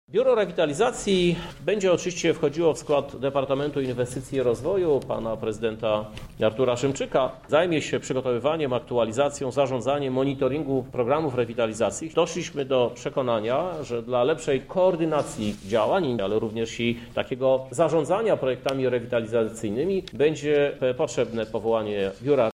O zmianach w funkcjonowaniu wydziałów mówi Prezydent Miasta Krzysztof Żuk.